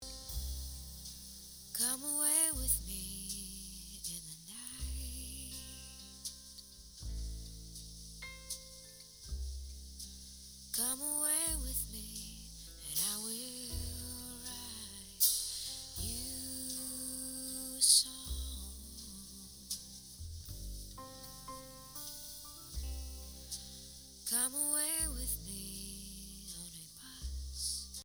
quand je joue l'originale avec phase inversé avec le fichier à 4 conversions, il en reste bien moins de son que dans l'exemple ci-dessus => ce qui veut dire que le fichier à 4 conversions est bien plus proche de l'originale